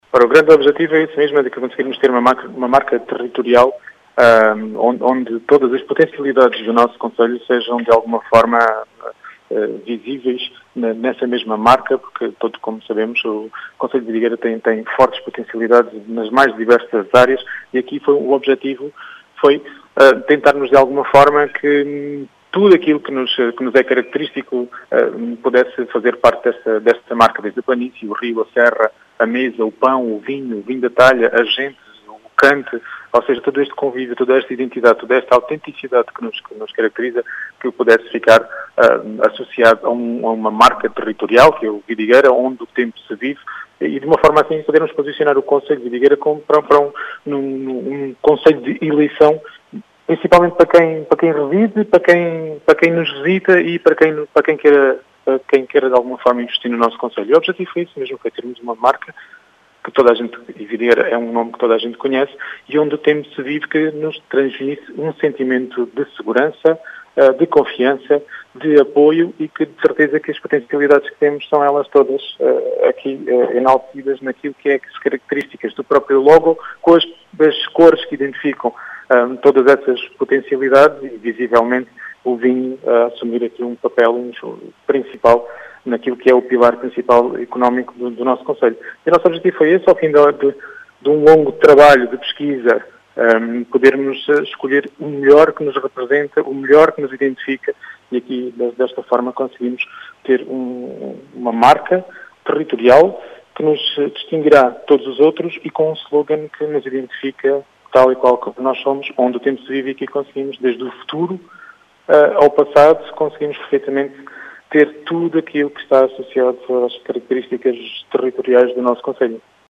As explicações foram deixadas por Rui Raposo, presidente da Câmara de Vidigueira, que fala num espaço “polivalente”, para “acolher” os visitantes do concelho.